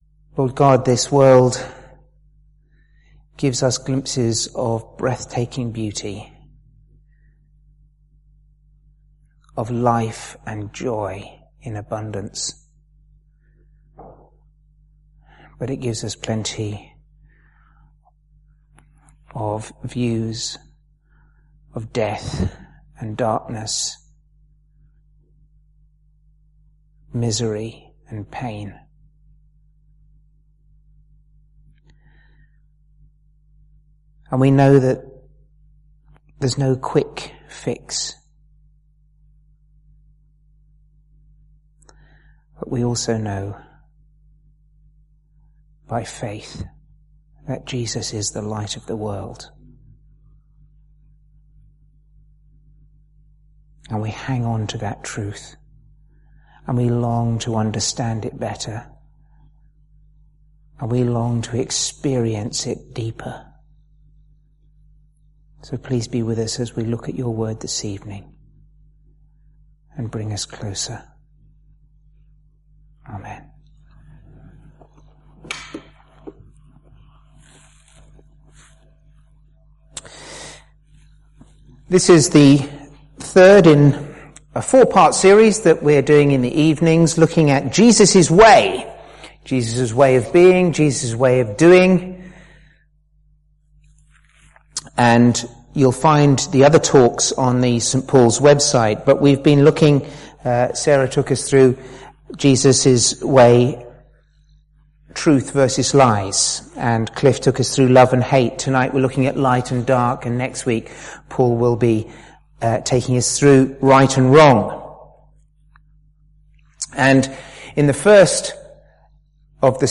Sermons
St Paul’s Church, Jersey